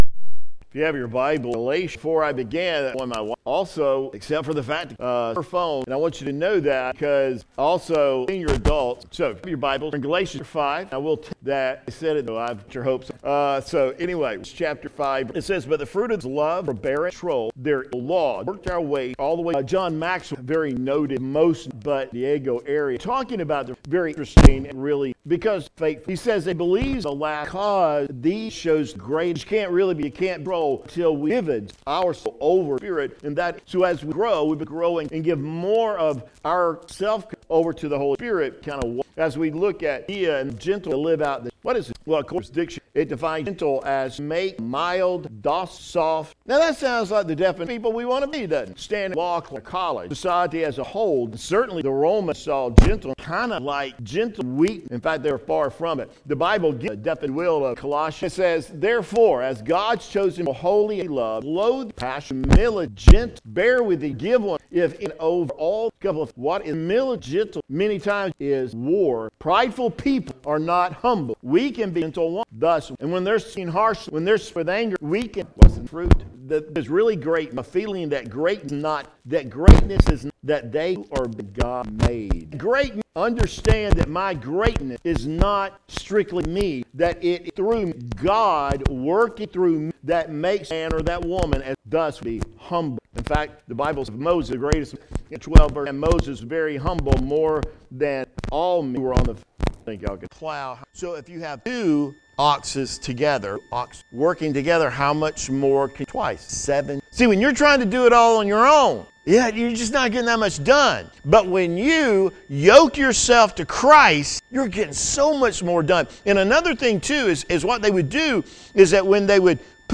(We apologize for the gap in the audio file due to microphone failure - see facebook video for complete sermon)